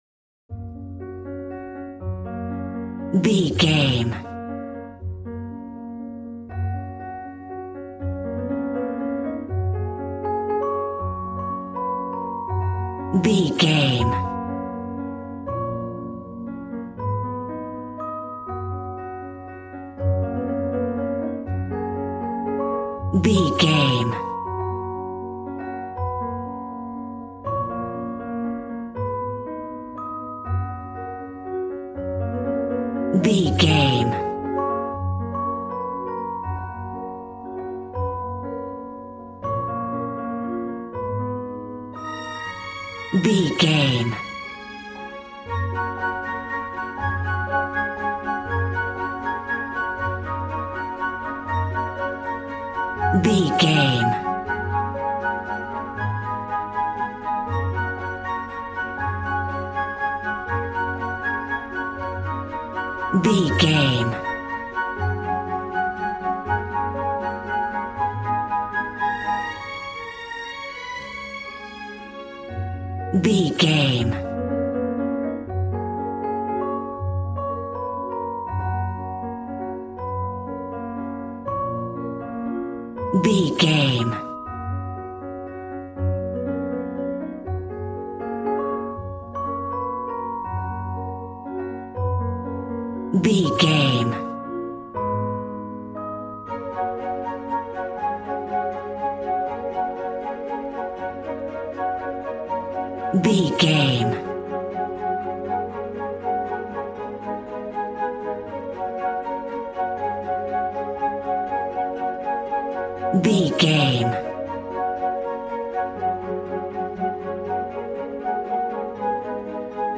Regal and romantic, a classy piece of classical music.
Ionian/Major
G♭
strings
violin
brass